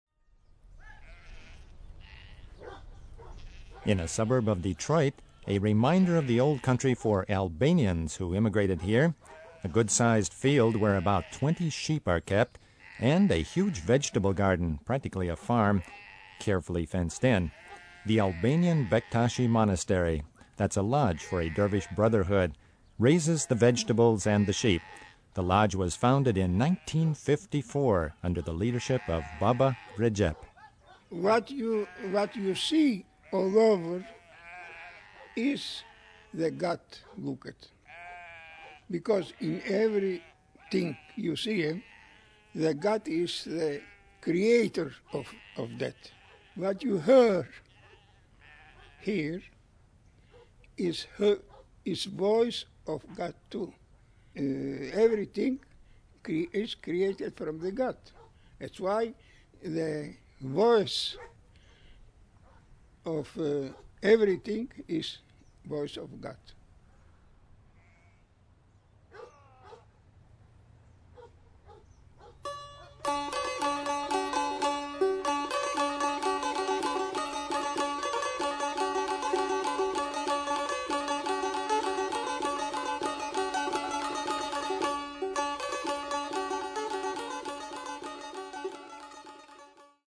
ALBANIAN
A full-scale Albanian wedding in Detroit